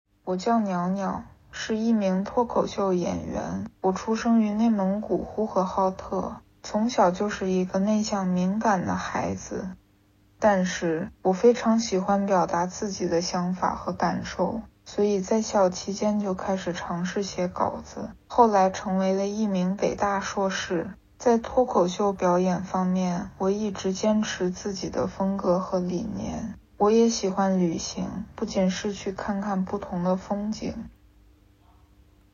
（这样的语速和情绪，倒是差点被烦死了。）
鸟鸟30s自我介绍.m4a